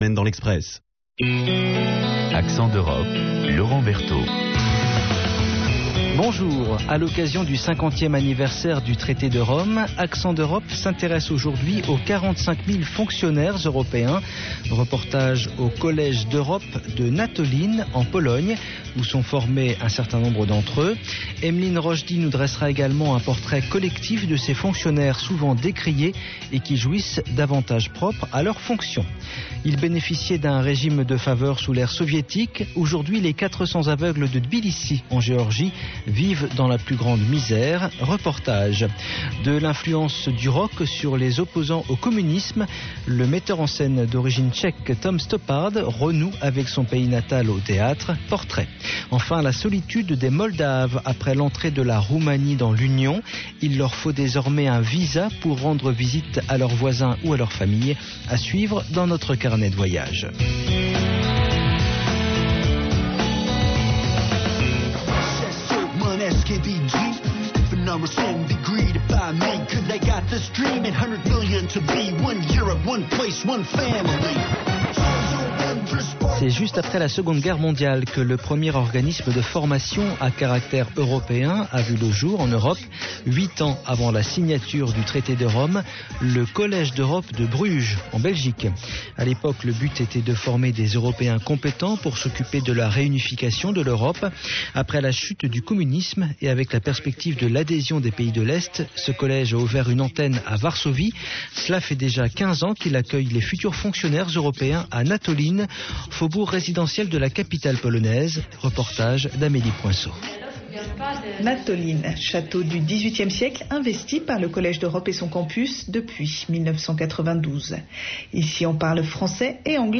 Reportage : La cité des aveugles à Tbilissi en Géorgie, une relique curieuse du passé soviétique .